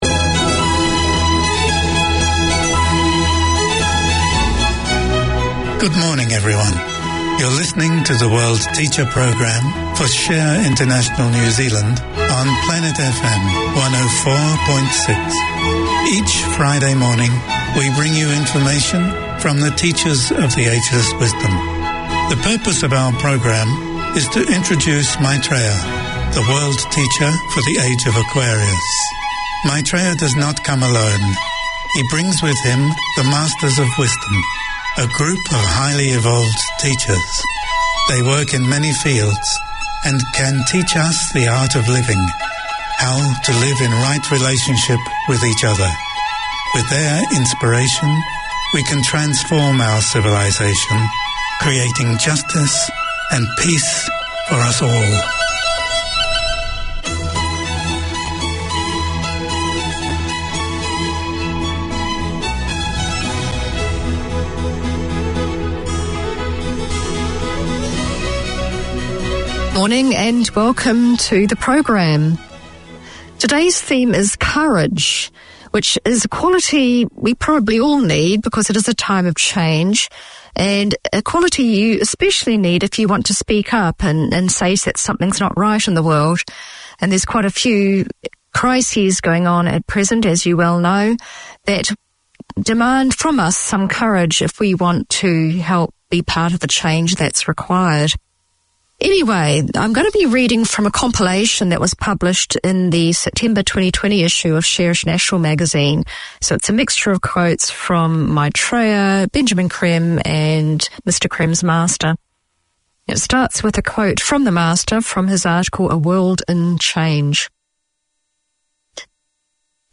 Community Access Radio in your language - available for download five minutes after broadcast.
From Artificial Intelligence to Zoology, working scientists are bringing science to a street near you. This show talks about science topics and their relevance to our everyday lives in a language that is understandable to the person on the street. Fascinating discussions are delivered along with ‘hot-off-the-press’ science news and a curious selection of the favourite music of scientists.